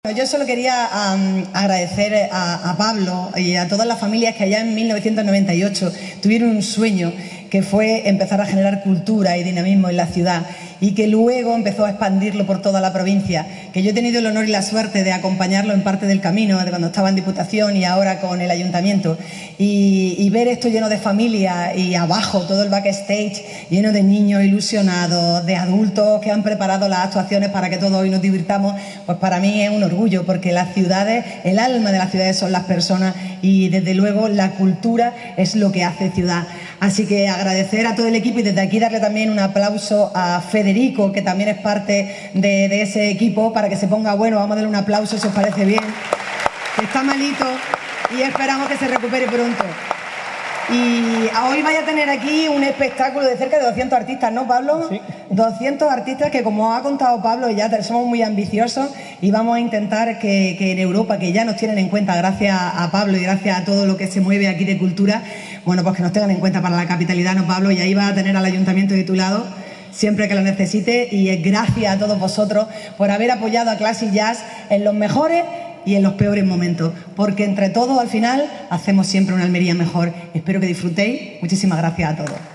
La alcaldesa arropa a Clasijazz en su Gala 2025 ‘fin de curso’ en el Auditorio
CORTE-ALCALDESA-4.mp3